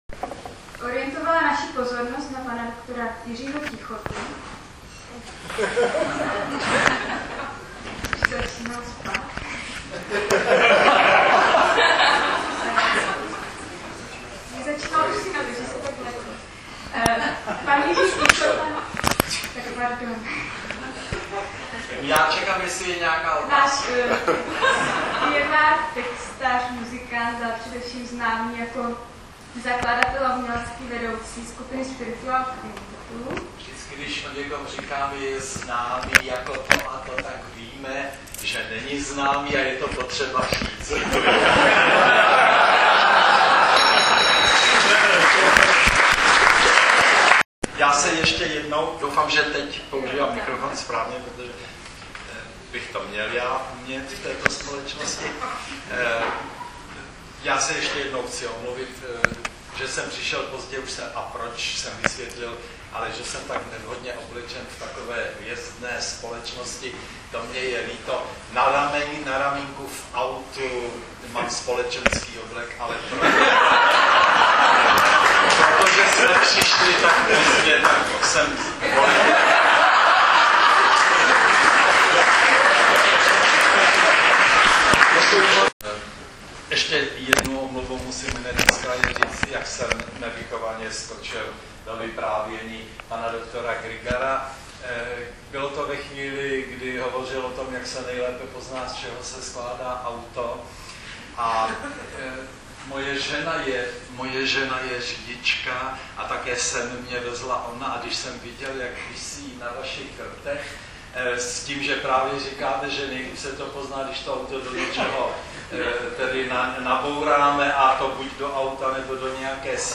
Besedovali tady čtyři známí muži, kteří všichni nesou jméno Jiří -Čunek, Pavlica, Grygar a Tichota. Jde o záznam větší části besedy (omlouvám se, chybí začátek), kterou uspořádala Česká křesťanská akademie v Blatnici, které se daří zvát řadu zajímavých hostů...
Prvním vteřinám zřejmě neporozumíte, po 25 sekundách ale začíná "srozumitelné" povídání Jiřího Tichoty, následuje povídání dalších mužů...